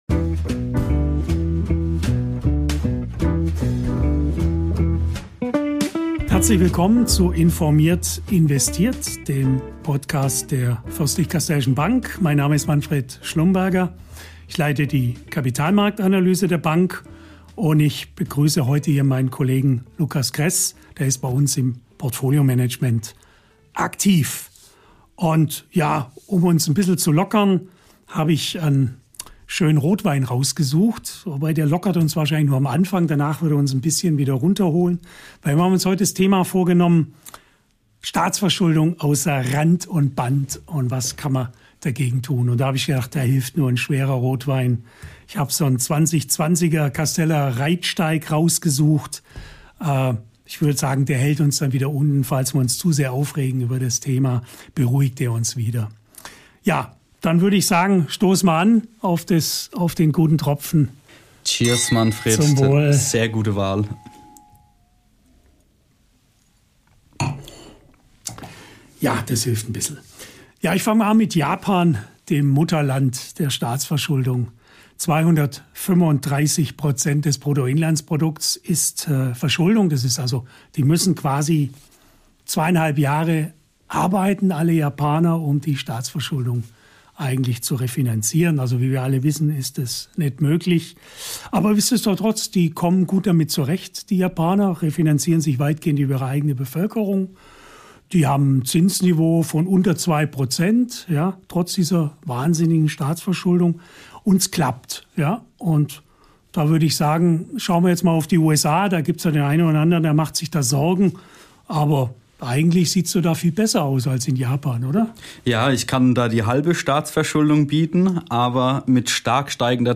Ehrlich, humorvoll und diesmal bei einem Glas Rotwein!